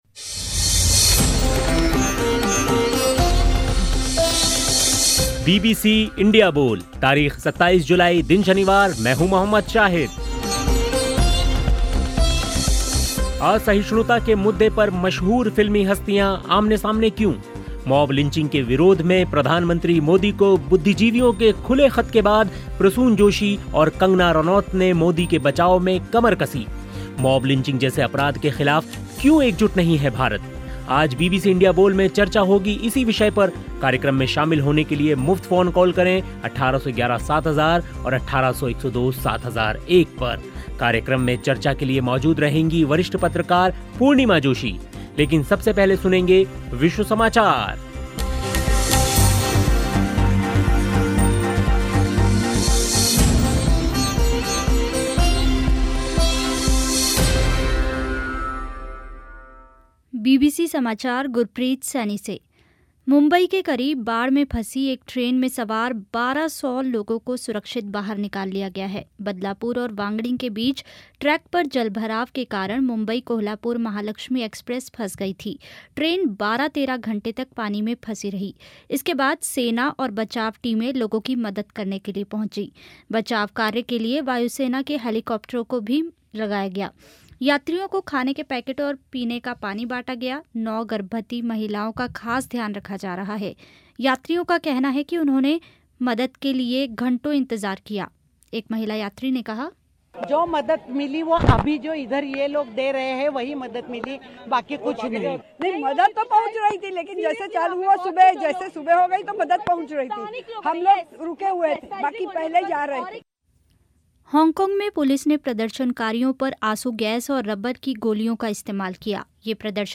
मॉब लिंचिंग जैसे अपराध के ख़िलाफ़ क्यों एकजुट नहीं है भारत? बीबीसी इंडिया बोल में इस सप्ताह चर्चा इसी विषय पर हुई.
लेकिन सबसे पहले विश्व समाचार सुनिए.